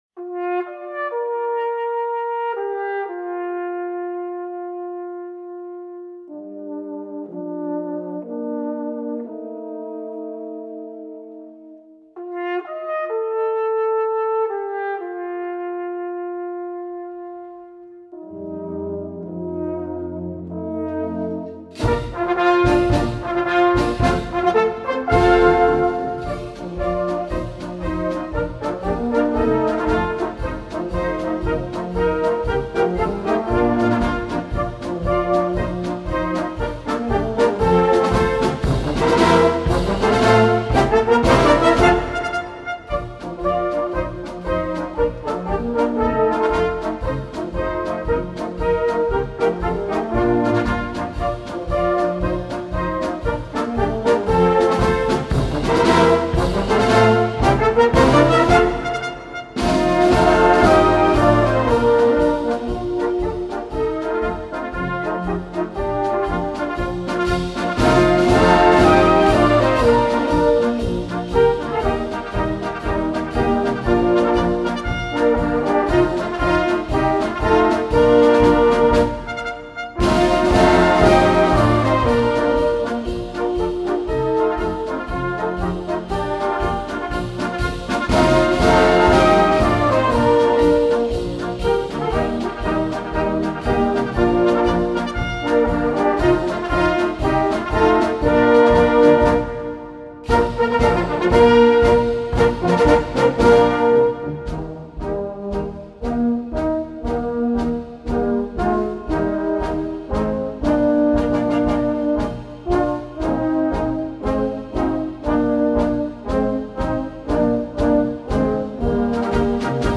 seine Heimat blasmusikalisch .